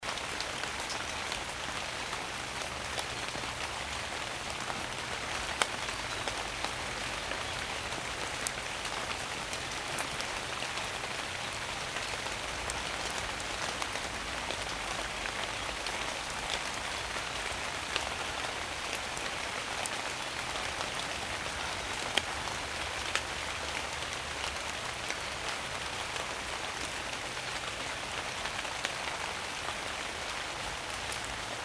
Field Recording
Sitting in my car listening to the rain on Thursday Rain hitting the roof of my car
Rain.mp3